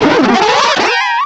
pokeemerald / sound / direct_sound_samples / cries / floatzel.aif